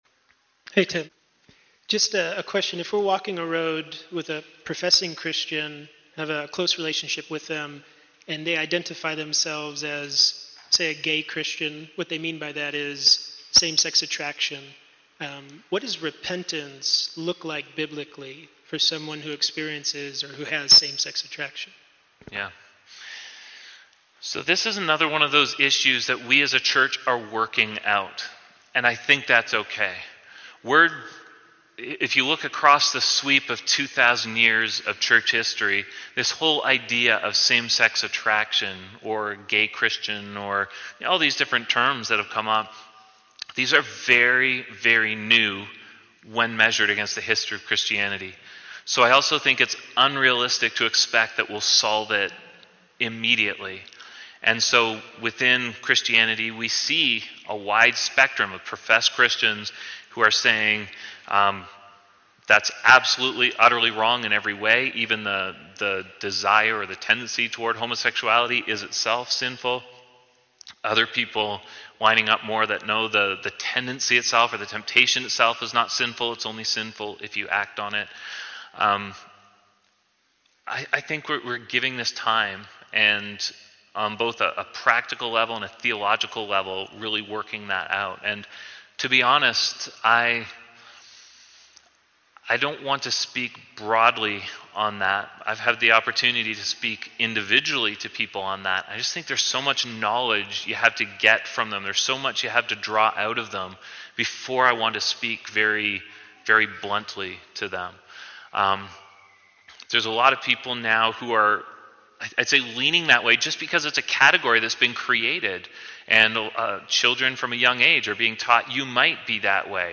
responded to a series of questions posed by the members of an audience at Antioch Bible Church in Roodepoort, South Africa.